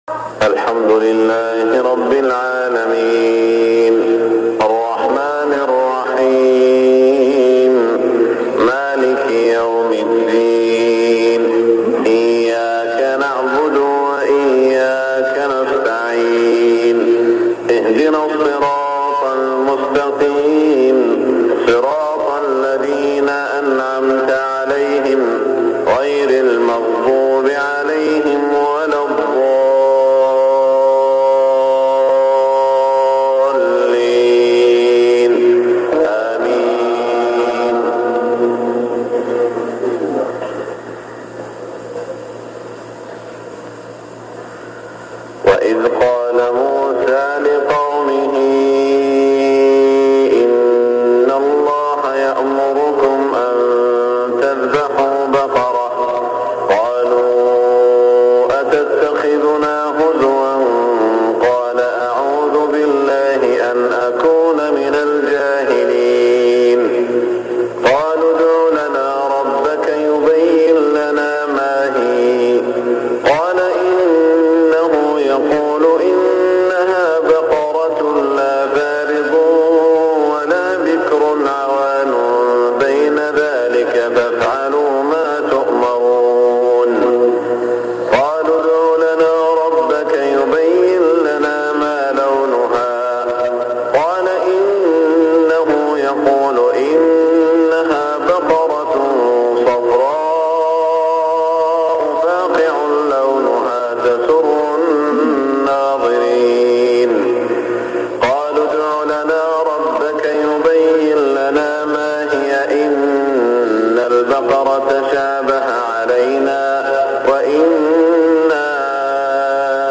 صلاة الفجر 1418هـ من سورة البقرة > 1418 🕋 > الفروض - تلاوات الحرمين